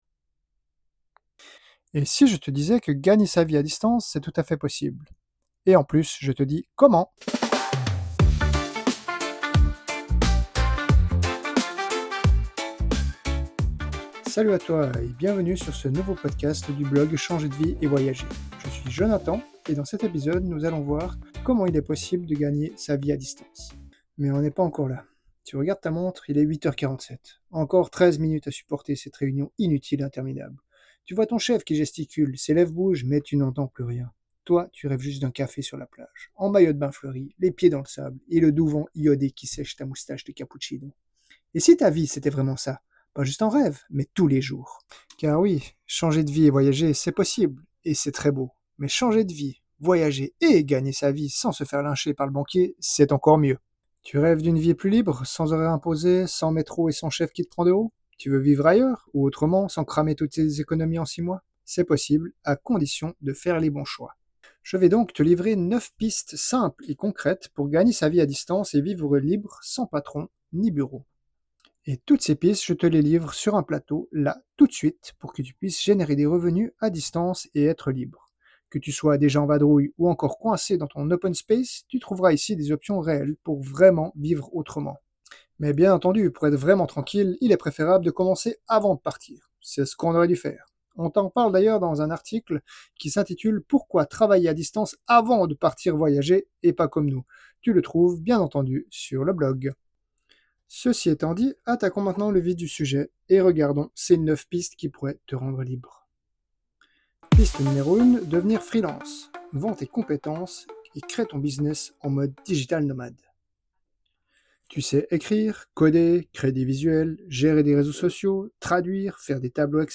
Tu peux le lire si tu préfères les mots à la voix, mais promis : à l’oral, je t’embarque avec un ton plus direct (et quelques sourires en bonus).